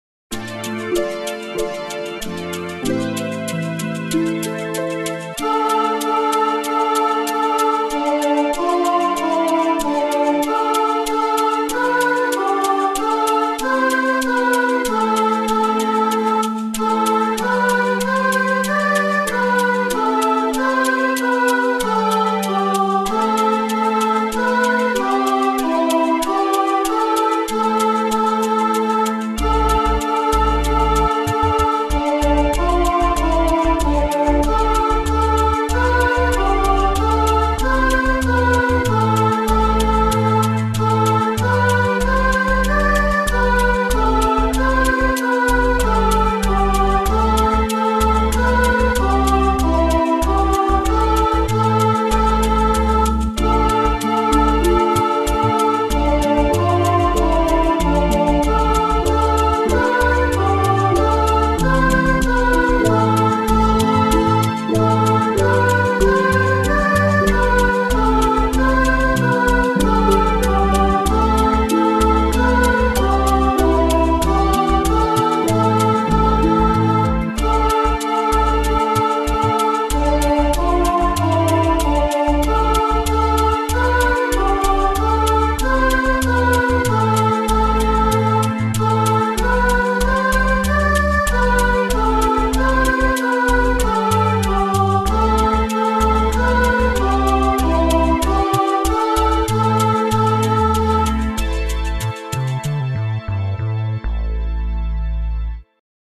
“When Jesus to the Jordan Came” is chant – text by Ralph Wright OSB and set to JESU DULCIS MEMORIA (Chant Mode 1).
As I may have mentioned before BIAB CAN’T DO CHANT!
So this time I just had some fun.